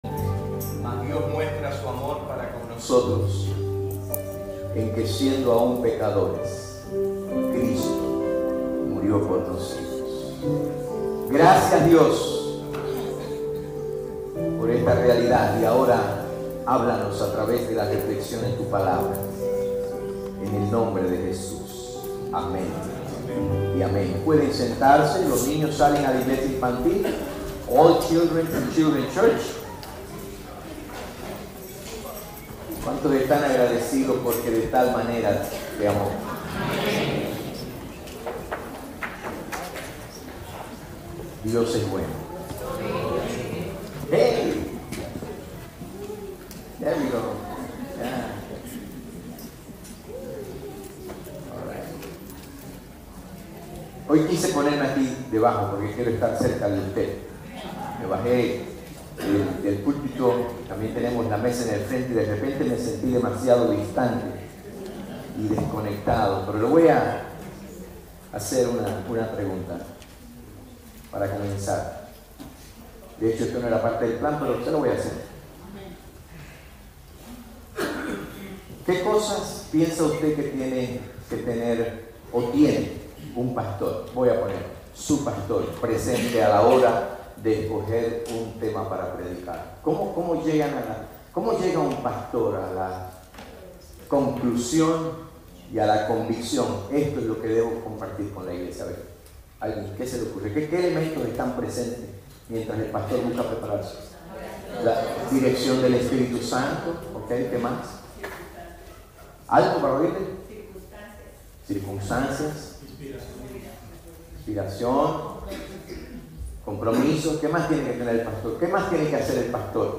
Sermones - PRIMERA IGLESIA BAUTISTA HISPANA